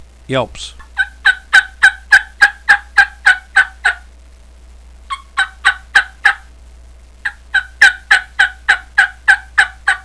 primosraspydoublehookyelps10.wav